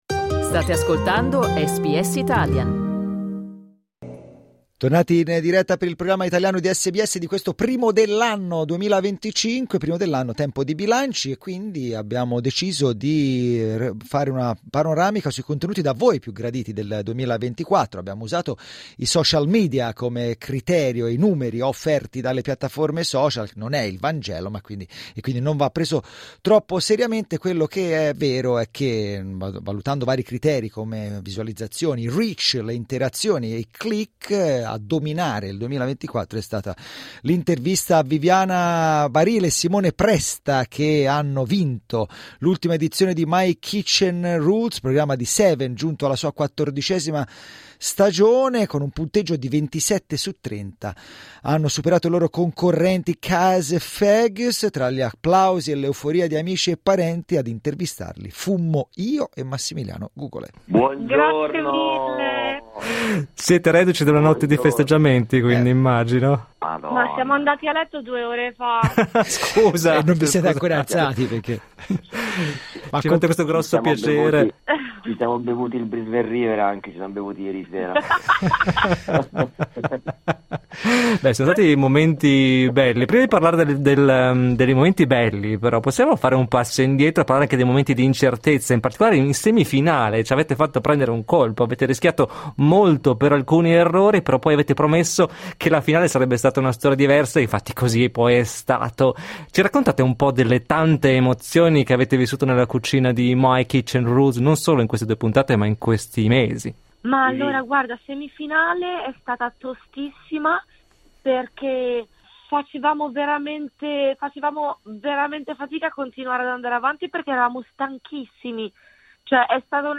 Abbiamo selezionato le interviste, i servizi e i post che più avete ascoltato, gradito e commentato sui social negli ultimi 12 mesi.